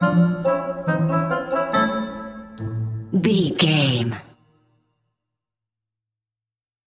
Uplifting
Aeolian/Minor
Slow
percussion
flutes
piano
orchestra
double bass
accordion
silly
comical
cheerful
Light hearted
quirky